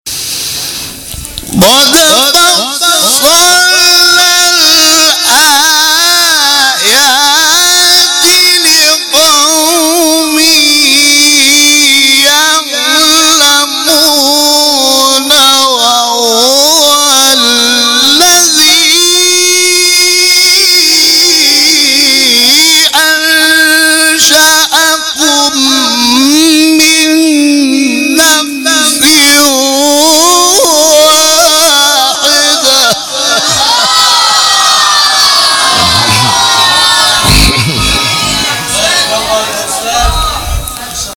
شبکه اجتماعی: فرازهای صوتی از تلاوت قاریان ممتاز کشور را می‌شنوید.
سوره انعام در مقام رست